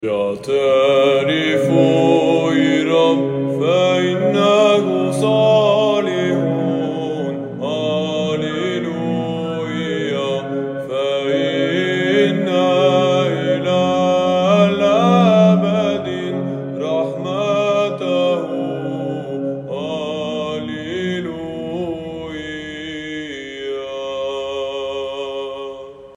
Arabic orthodox chant